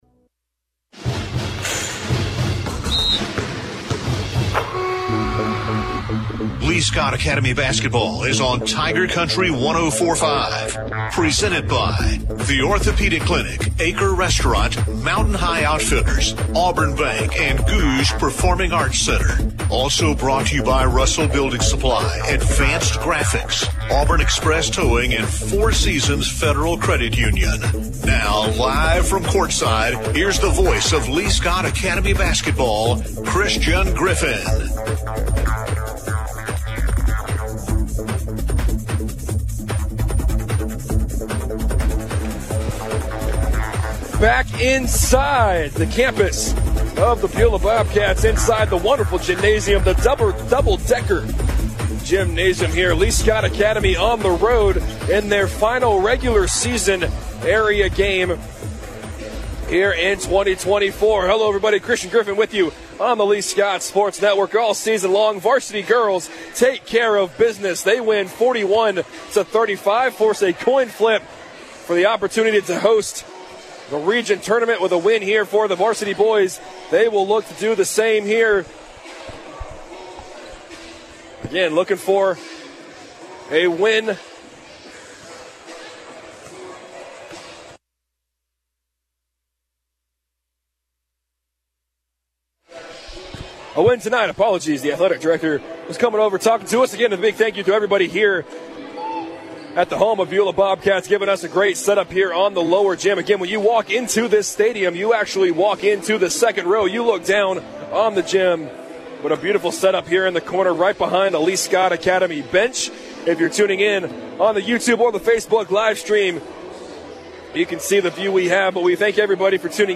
calls Lee-Scott Academy's game against the Beulah Bobcats. The Warriors won 80-60.